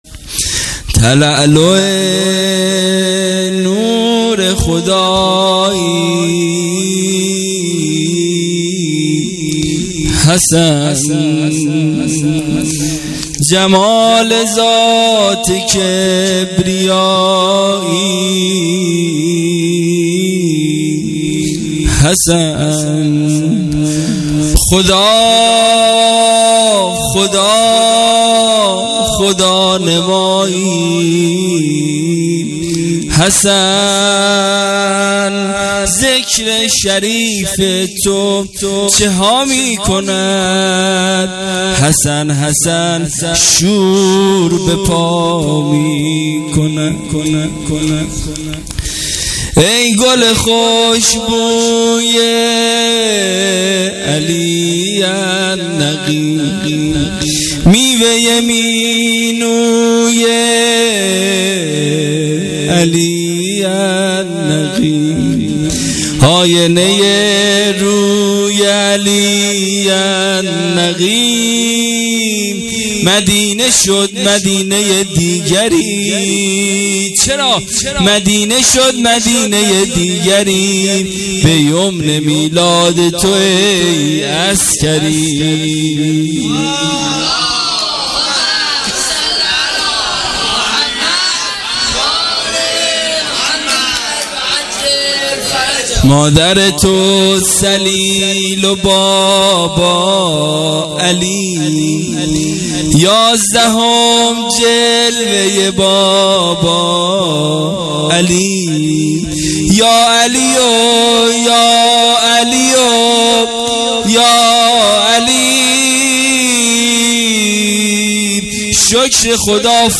ولادت امام حسن عسکری (ع) 98